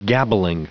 Prononciation du mot gabbling en anglais (fichier audio)
Prononciation du mot : gabbling